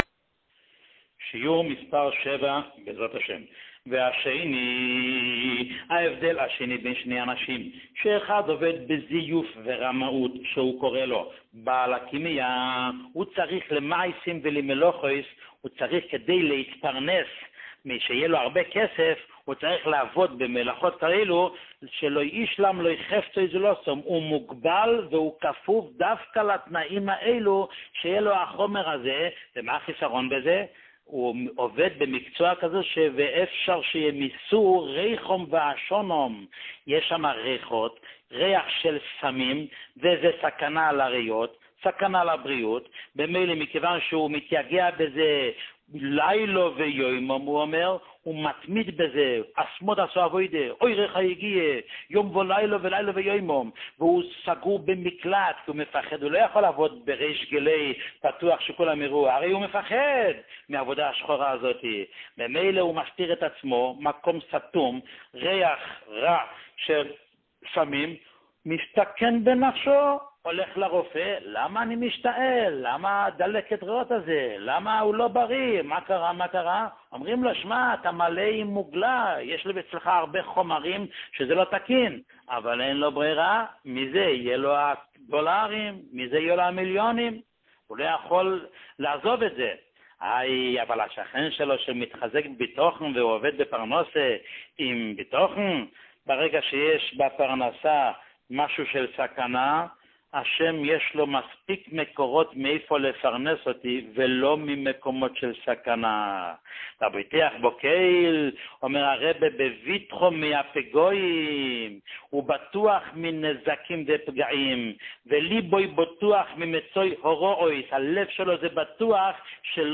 שיעורים מיוחדים
שיעור מספר 7